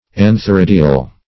An`ther*id"i*al, a.